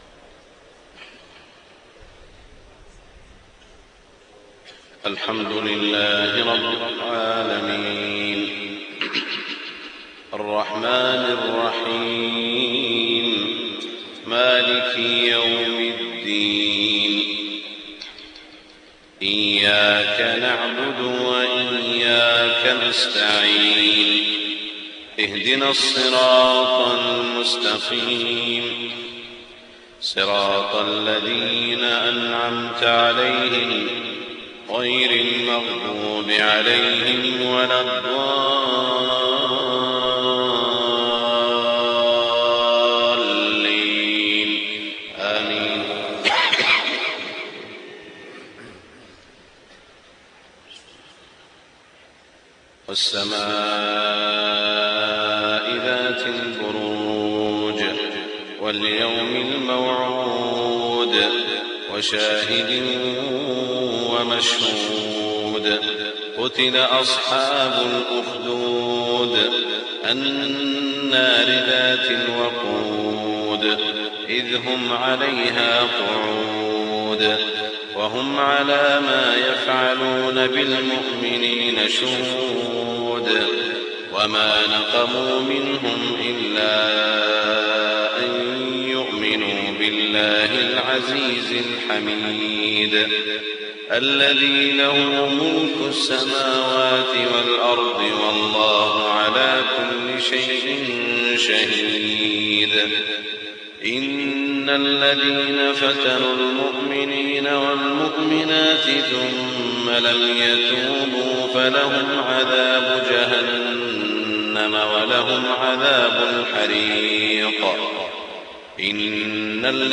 صلاة الفجر 24 ذو الحجة 1427هـ سورتي البروج و الغاشية > 1427 🕋 > الفروض - تلاوات الحرمين